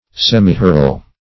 Semihoral \Sem`i*ho"ral\, a.